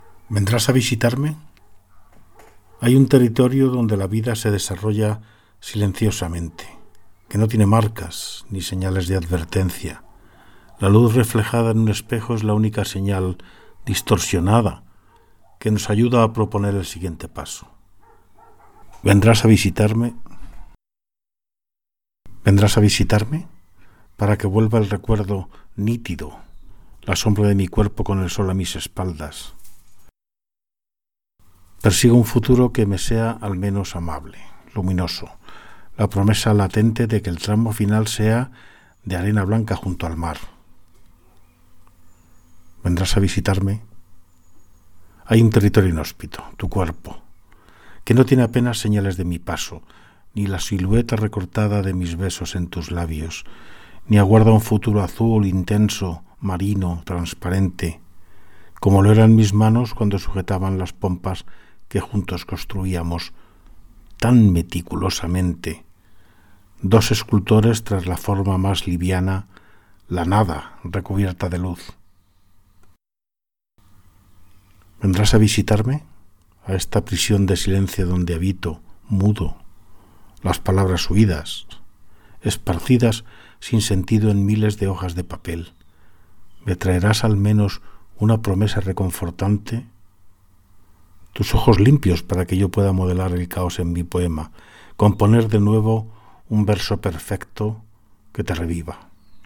Lectura del poema Vendrás a visitarme